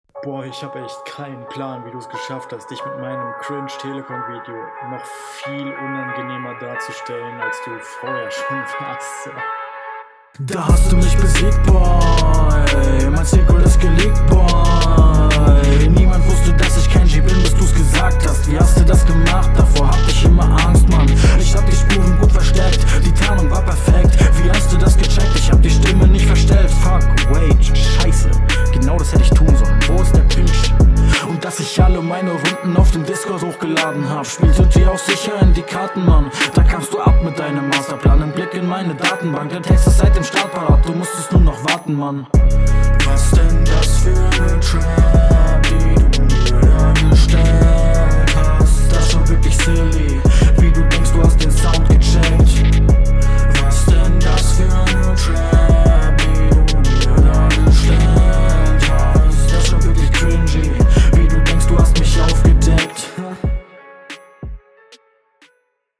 Flow:find ich ist im endeffekt deine stärke, was man hier auch merkt, wirkt routiniert, es …
Flow: Sehr nice zwischen halftime und normalen flow am wechseln und ist durchgehend unterhaltend Text: …